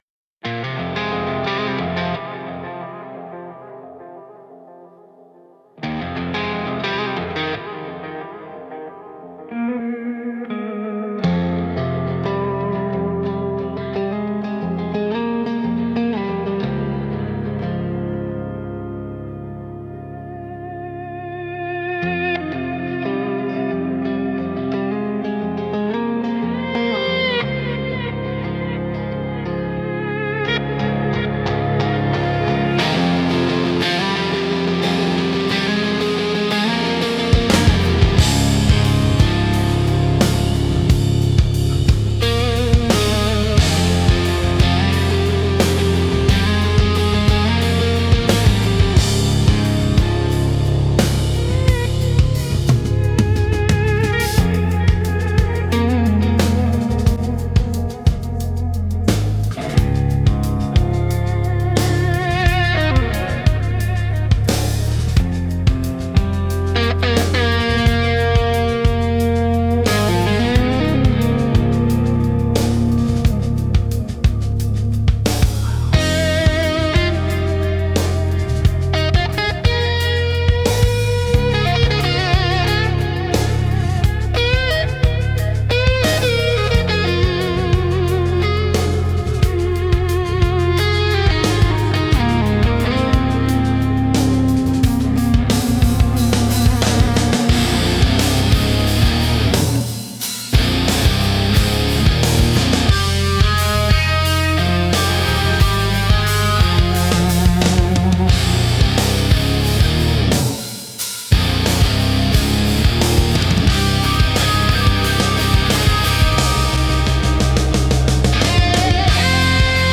Instrumental - Where the Pavement Sleeps 4.43